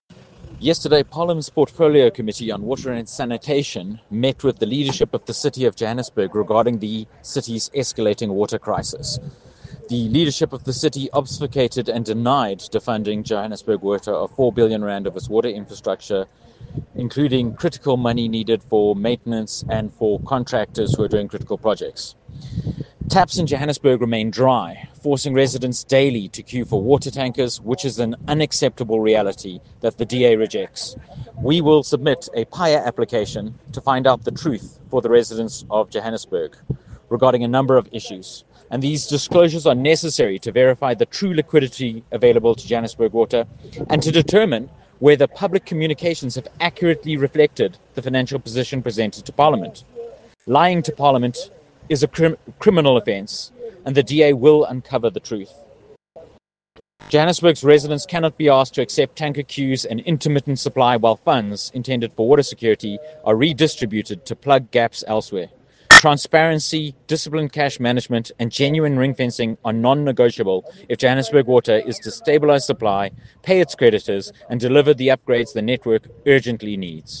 Soundbite by Stephen Moore MP.